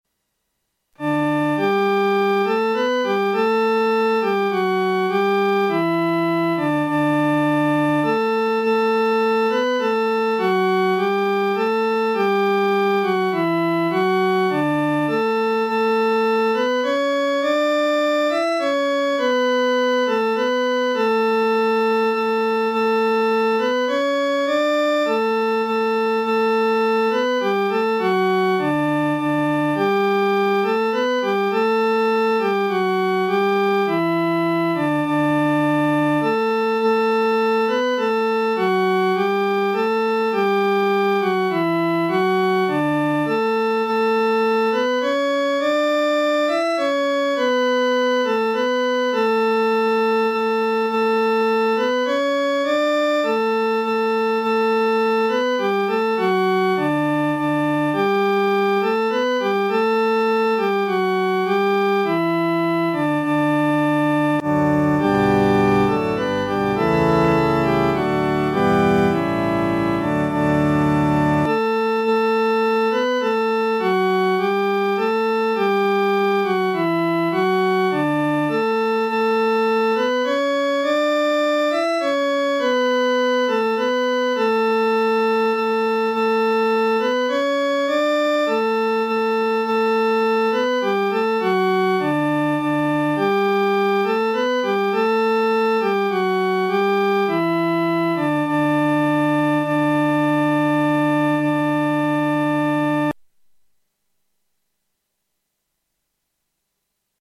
伴奏
女高
这首曲调格式简单，节奏鲜明，感情丰富，旋律多变，如同海浪起伏。